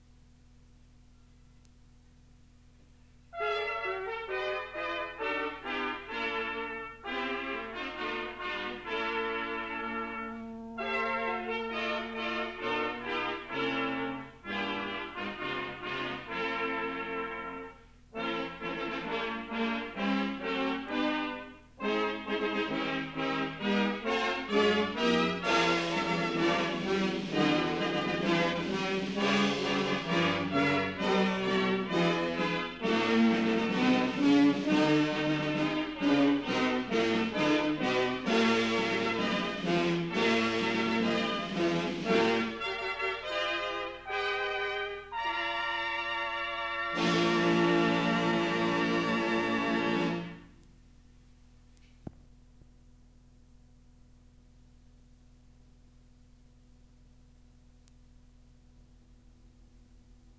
A “Fanfare for the Weavers” was written for the 850th Anniversary of the Company in W.J Burras in 1979 and first played by the Berisford’s Band at the Livery Dinner on 1st December 1979 in Vintners’ Hall.
FANFARE.wav